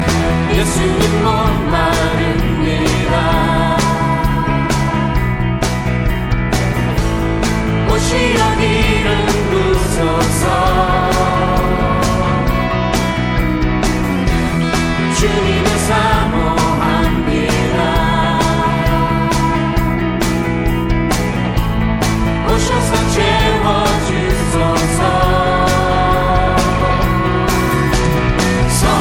• Category Gospel